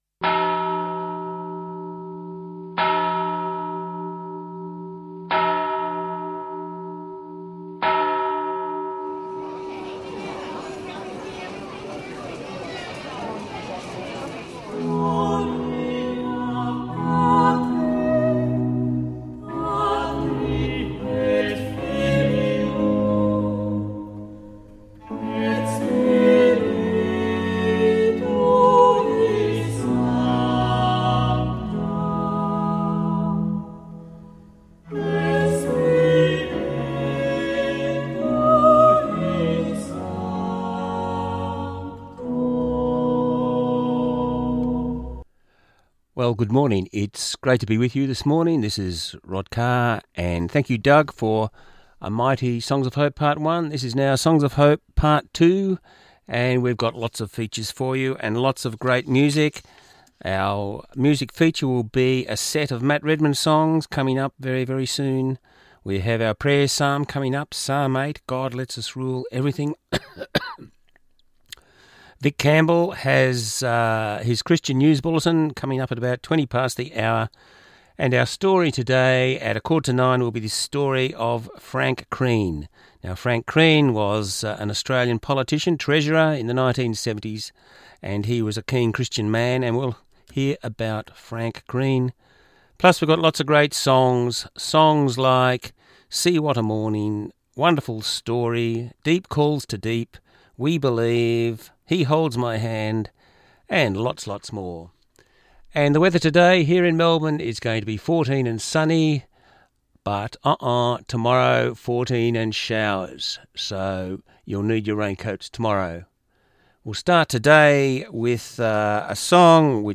2Jul17 1hr Christian Music